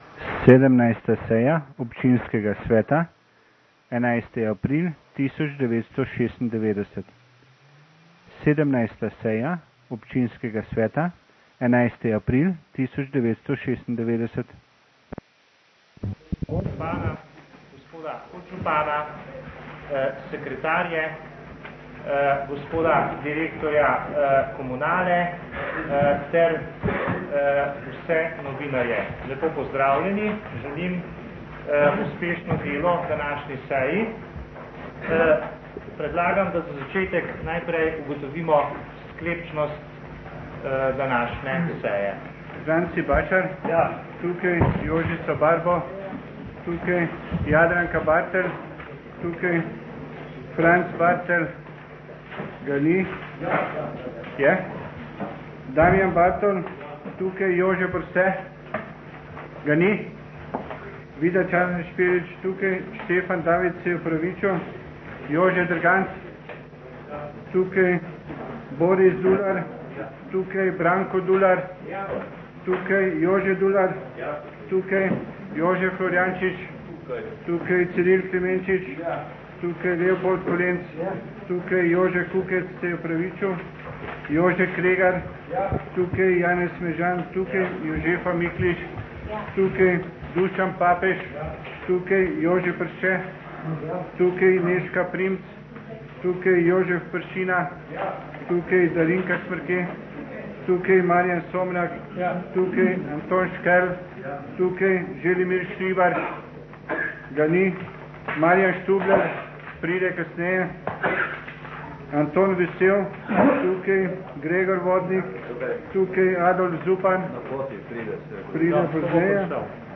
17. seja Občinskega sveta Mestne občine Novo mesto - Seje - Občinski svet - Mestna občina